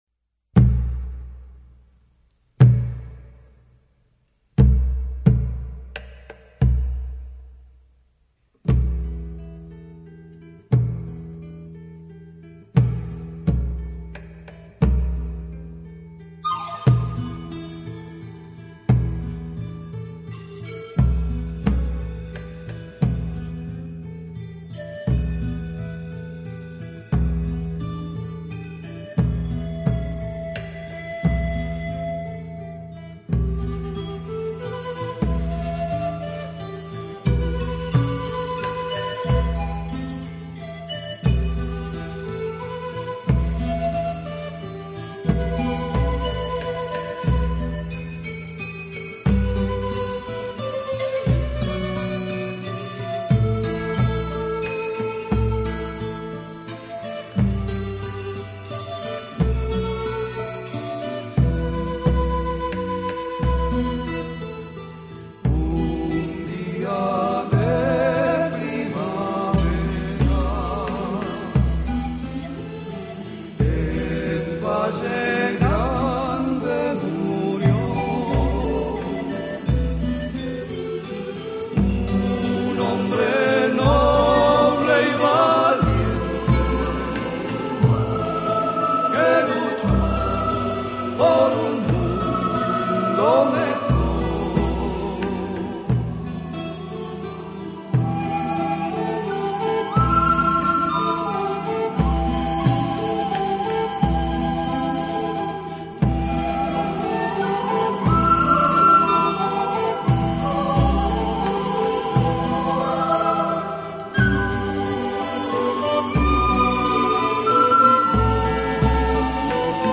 【南美民谣】
是以排萧、竖笛、吉它和一些传统民间乐器为主的山地民谣，尤其是在玻利维